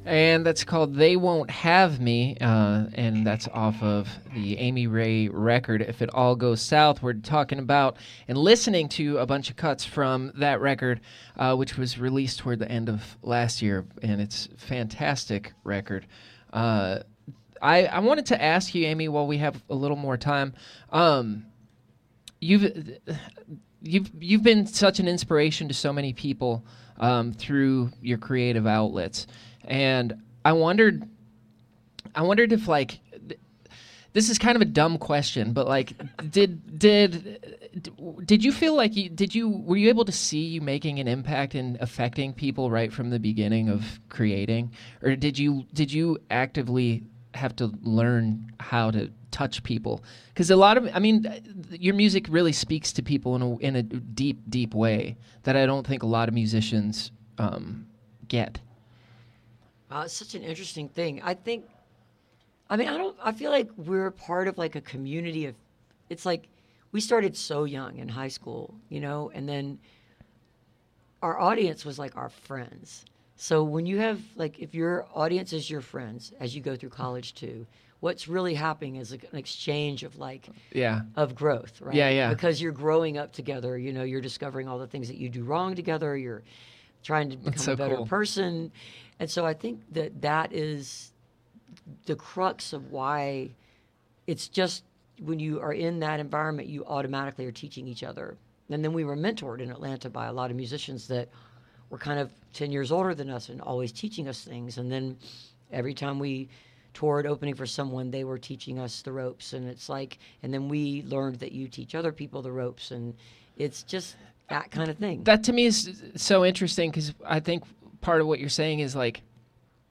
(captured from a webcast)
08. interview (5:55)